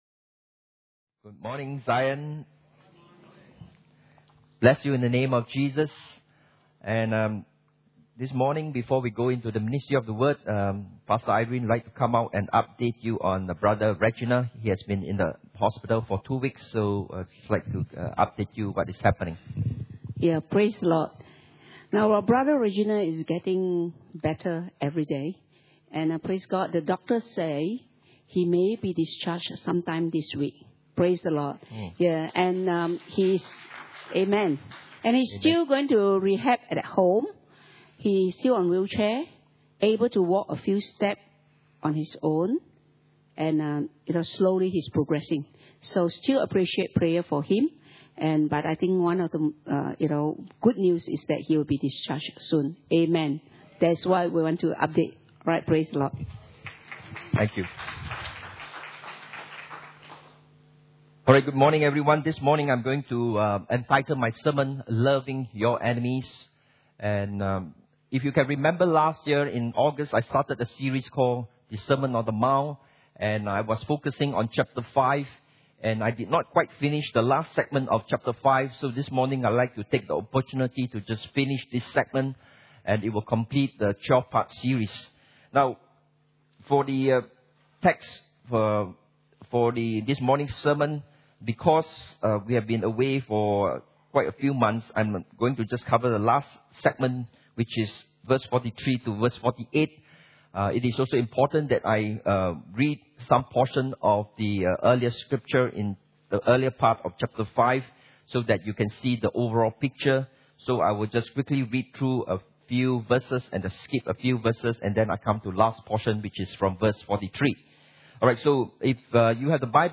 Sermon on the Mount P12 - Loving Your Enemies.mp3